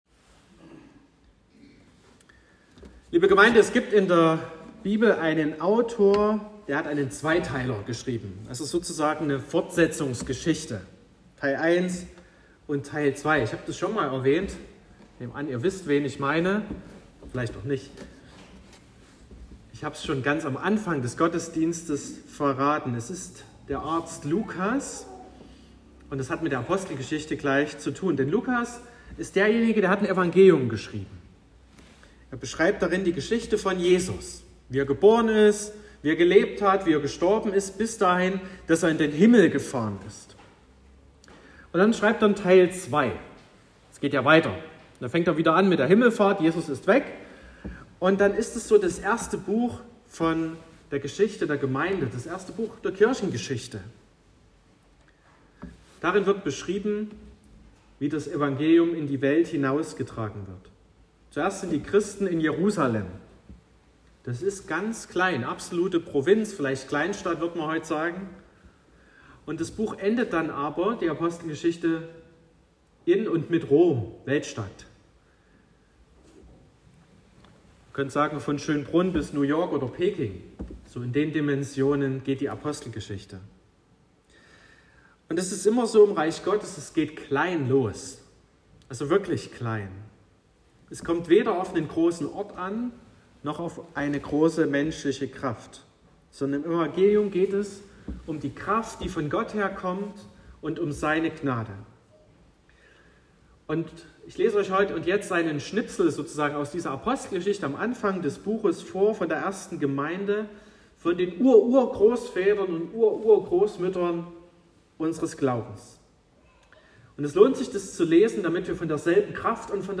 03.09.2023 – Gottesdienst zur Bibelwoche mit Konfi-Start
Predigt (Audio): 2023-09-03_Gemeinsam__grosszuegig_und_gnaedig__Bibelwoche_2023__Thema_1_.m4a (8,7 MB)